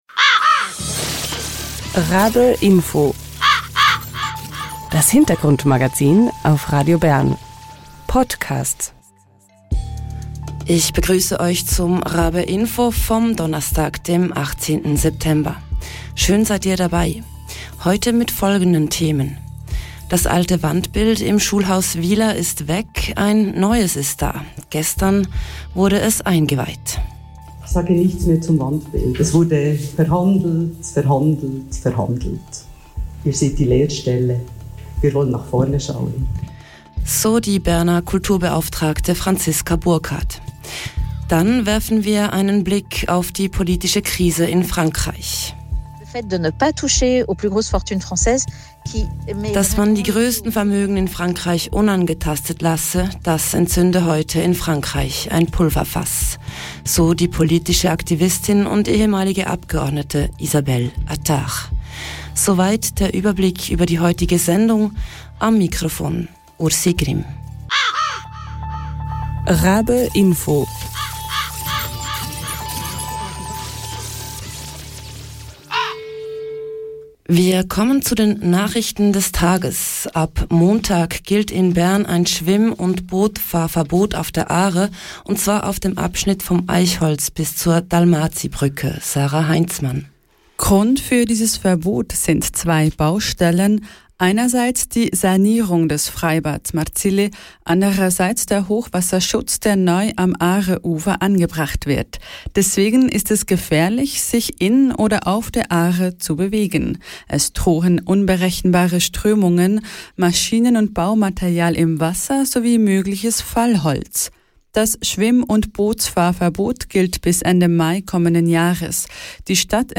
Im Interview hören wir über die Gründe für den Unmut und über die Ziele der neuen Bewegung «Bloquons tout».